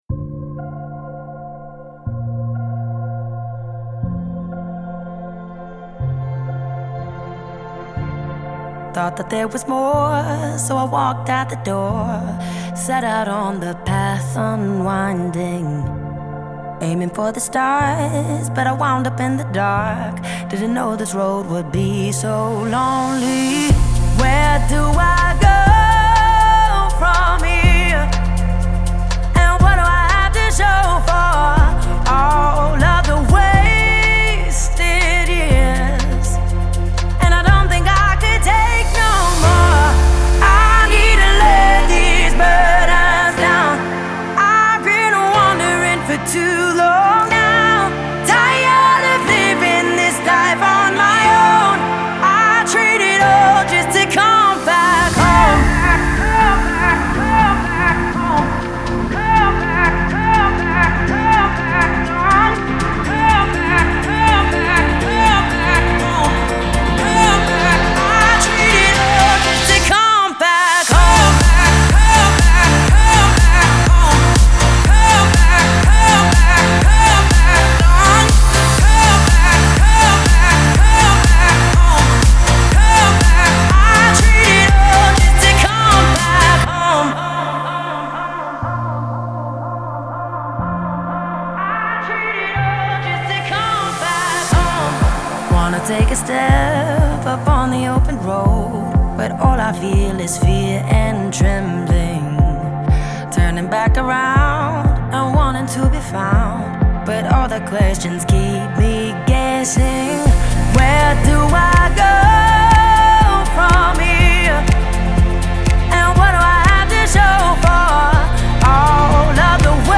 это трогательная песня в жанре христианской поп-музыки